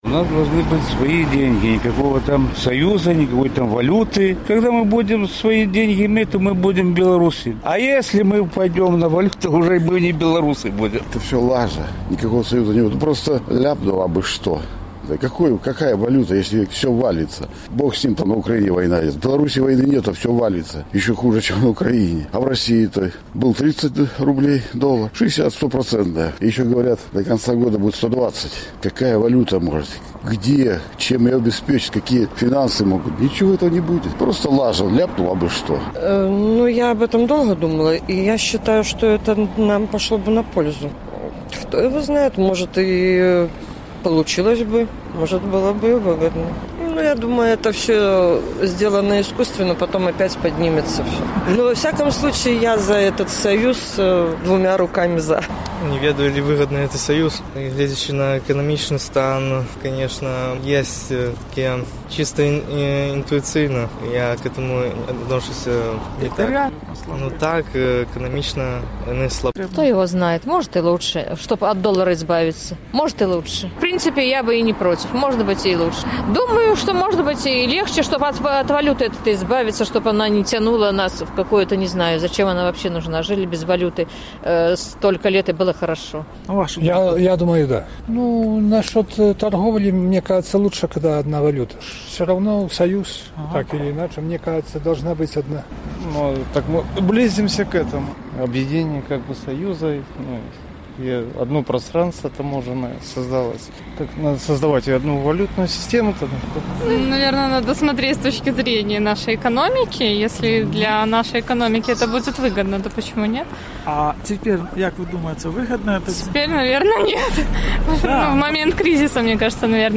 Ці трэба, каб Расея, Беларусь і Казахстан стварылі Валютны саюз? Апытаньне ў Горадні
З такім пытаньнем наш карэспандэнт зьвяртаўся да гарадзенцаў.